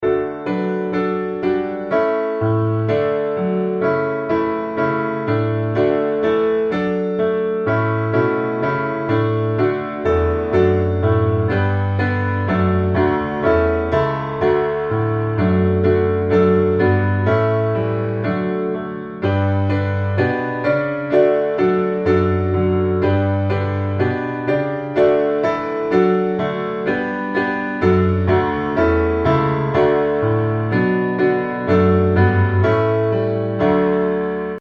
Gospel
D Major